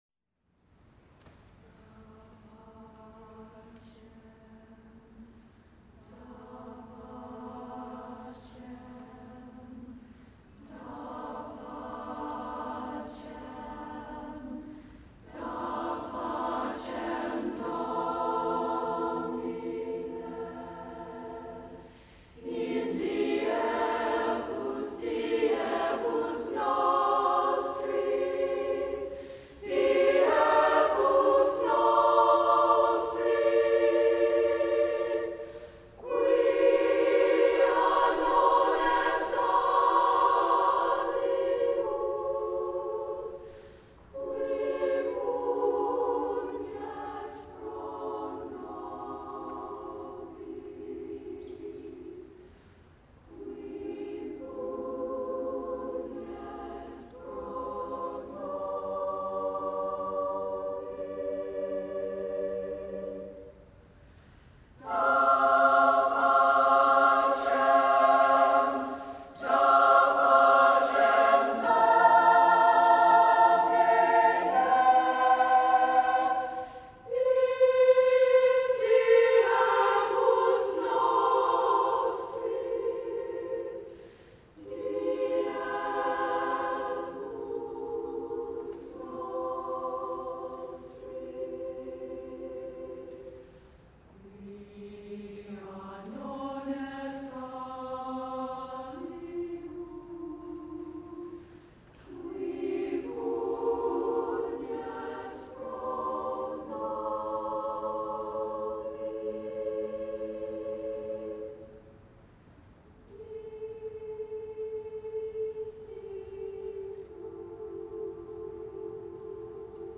Epoque: 20th century
Genre-Style-Form: Sacred
Type of Choir: SSAA  (4 women voices )